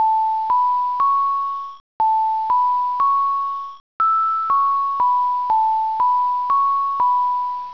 melodOSS(Open Sound System)デバイスである/dev/dspにsin波を書き込み、チープながら、リアルタイムに音階を出力する、メロディデーモン
マコトにチープな音質ながら「チューリップの歌」が4小節再生される。